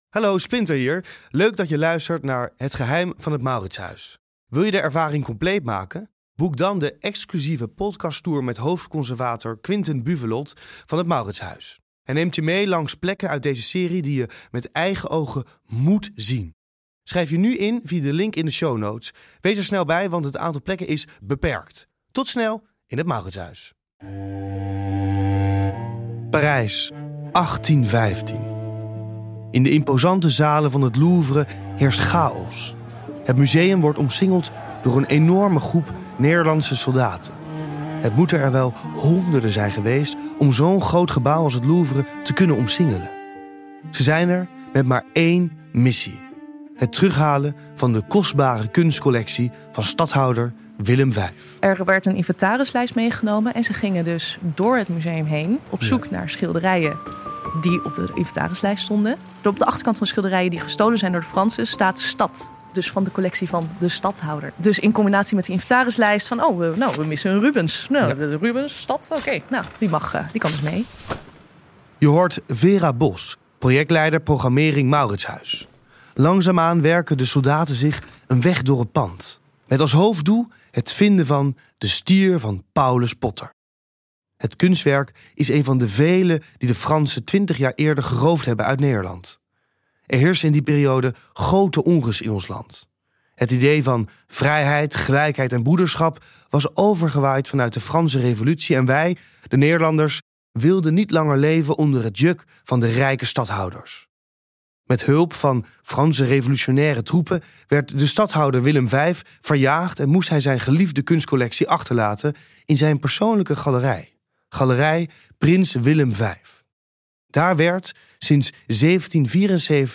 Splinter spreekt met experts en fans, en stuit onderweg op verrassingen die je niet zag aankomen.